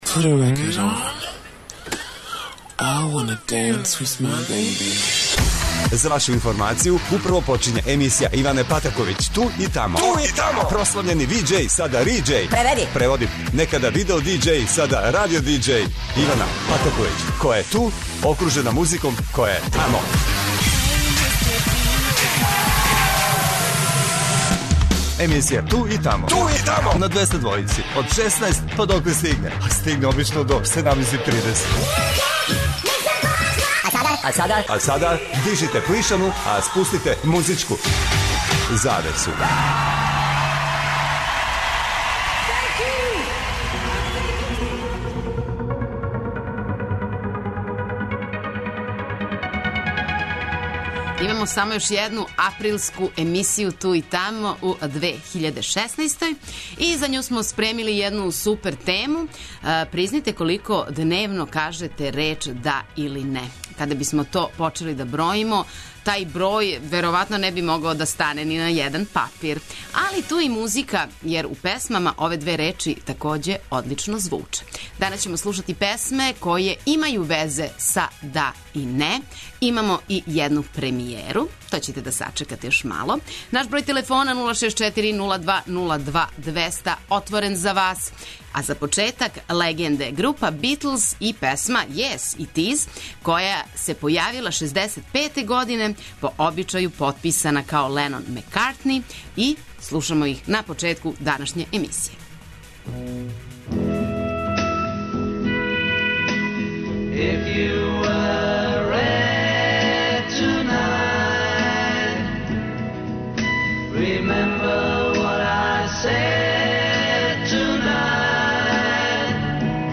Слушаоци Двестадвојке у сваком тренутку могу да се јаве и дају своје предлоге на задату тему.